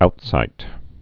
(outsīt)